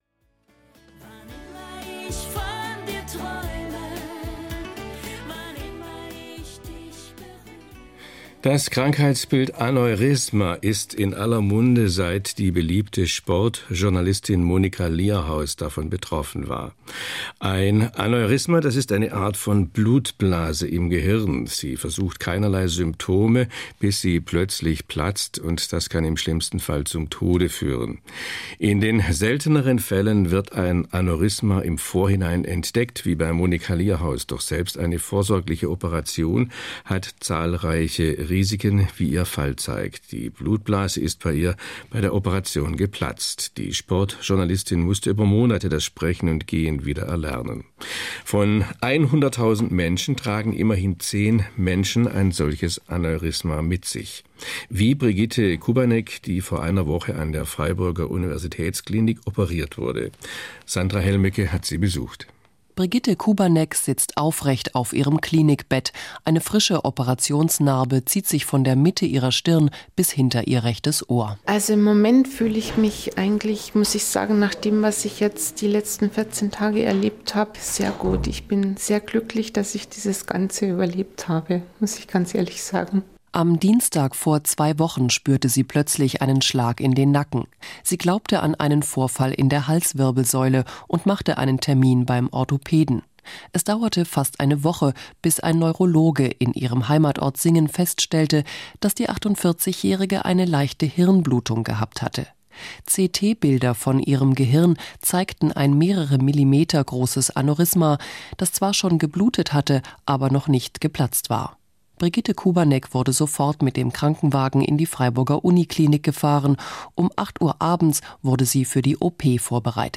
1. Radiobeitrag: Interview mit einer Aneurysma-Patientin, gesendet im SWR4 am 22.02.2011
interviewaneurysmapatient.mp3